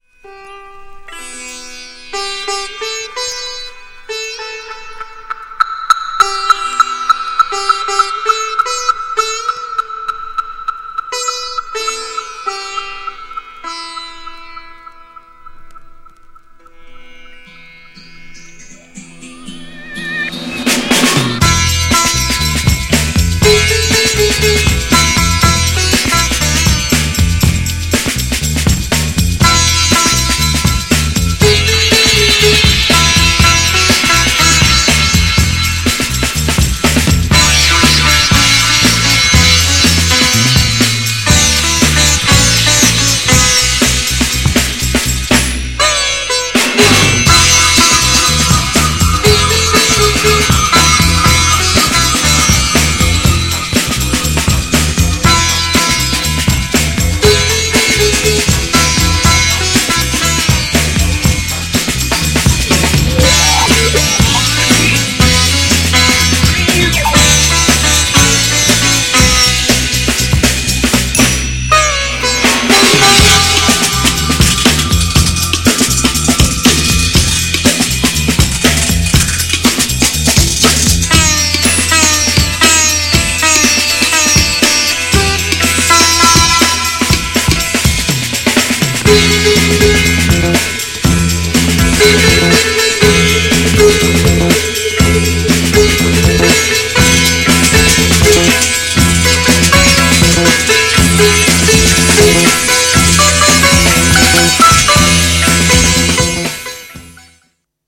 GENRE R&B
BPM 116〜120BPM